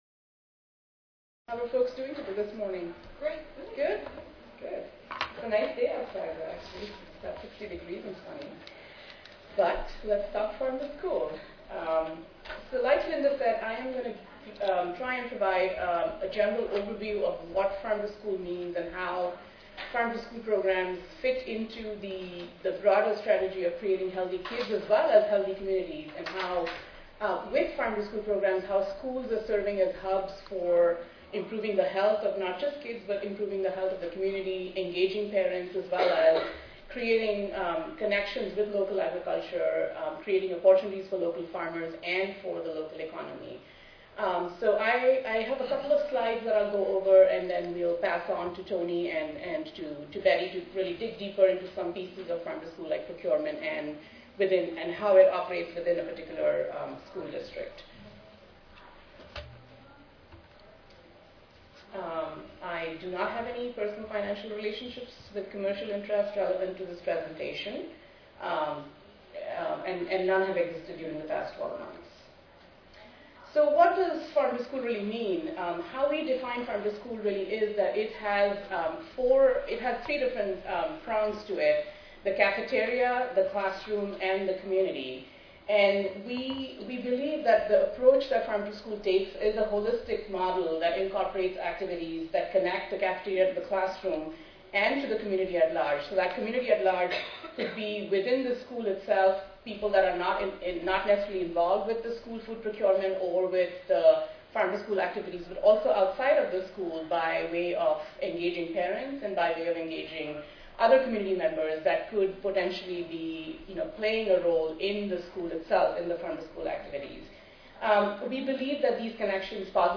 4103.0 Schools as Hubs for Healthy Communities Tuesday, November 1, 2011: 10:30 AM Panel Discussion More than one third of the U.S. adult population and 17% of U.S. children are obese.
Panelists in this session are leaders from the fields of Farm to School, sustainable procurement, school food reform, community engagement and food service.